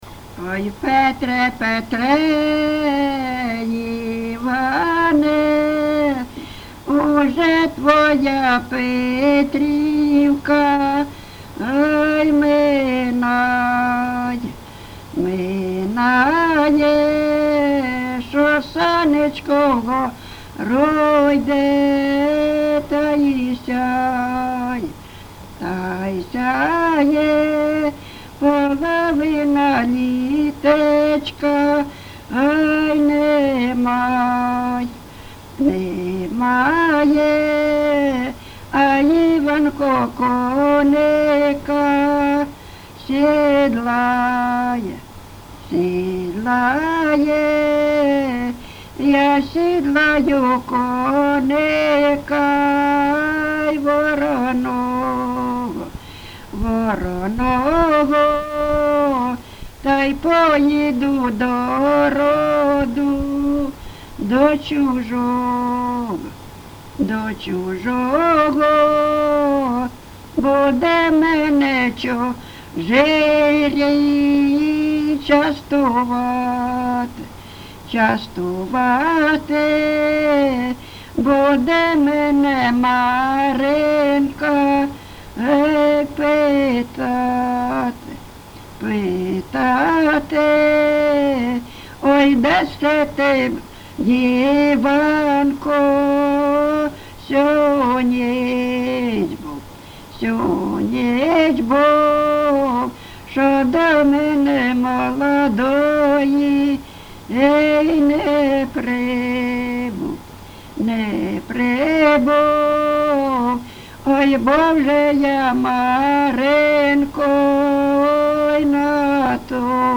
ЖанрПетрівчані
Місце записус. Привілля, Словʼянський (Краматорський) район, Донецька обл., Україна, Слобожанщина